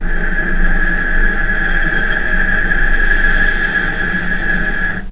vc-G#6-pp.AIF